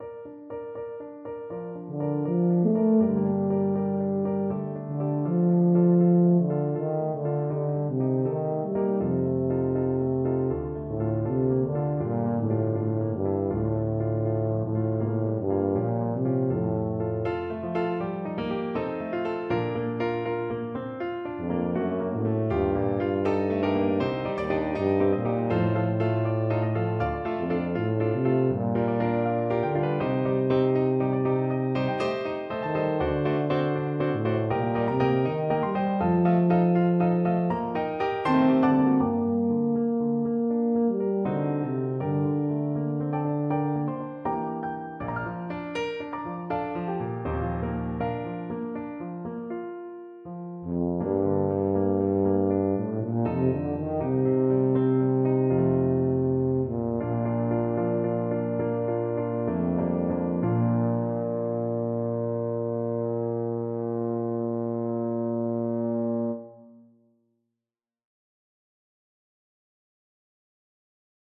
= 80 Moderato
4/4 (View more 4/4 Music)
Eb3-C5
Classical (View more Classical Tuba Music)